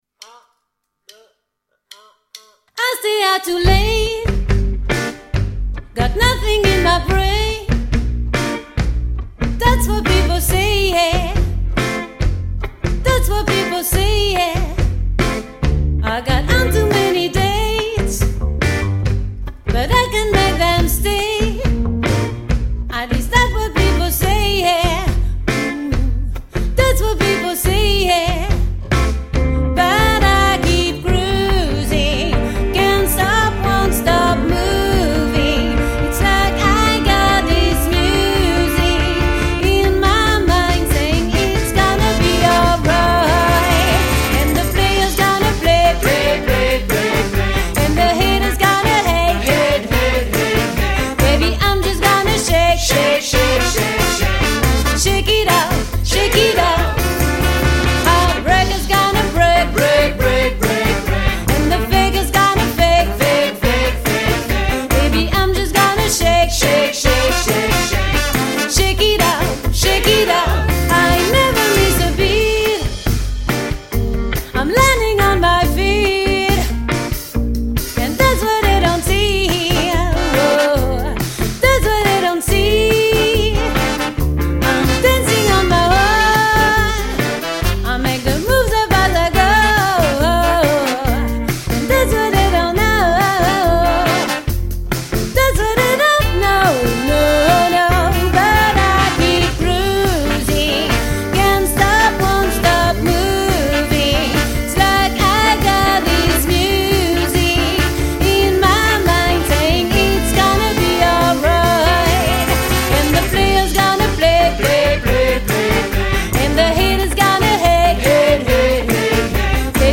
Jazz Swing Pop